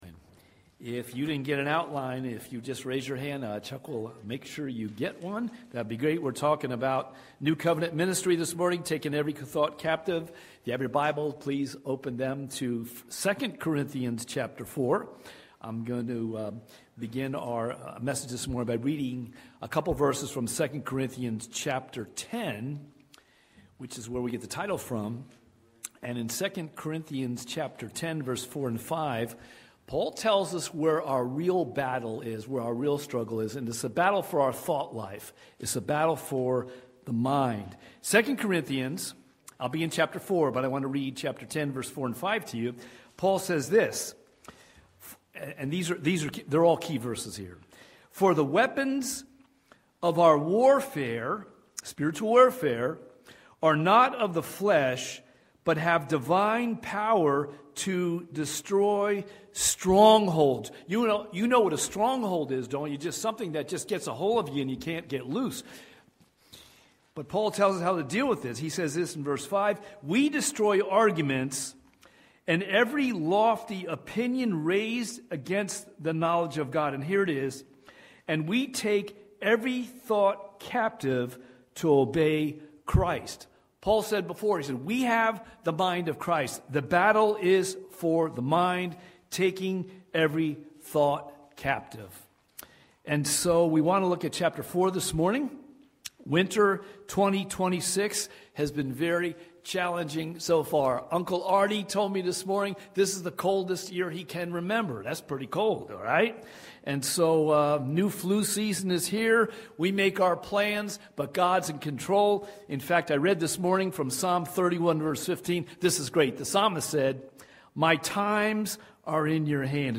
Watch Online Service recorded at 9:45 Sunday morning.
Sermon Audio